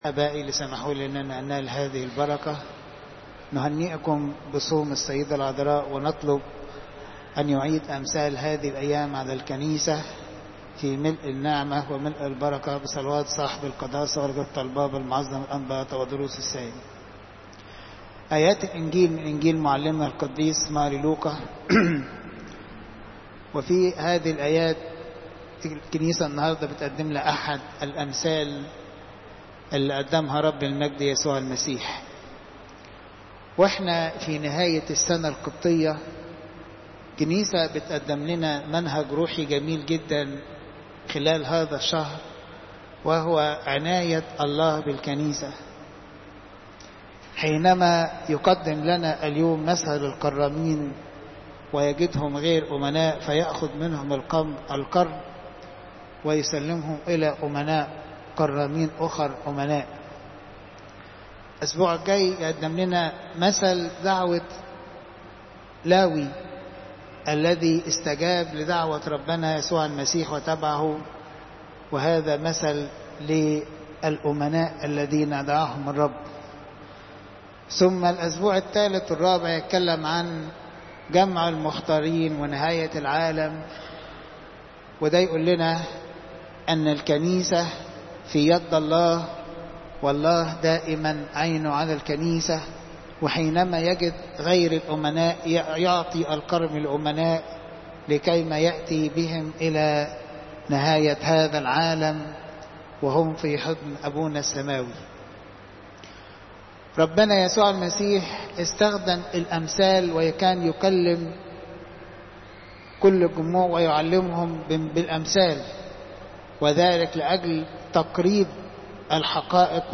Popup Player تحميل الصوت الانبا صليب الأحد، 09 أغسطس 2015 12:41 عظات قداسات الكنيسة الزيارات: 1599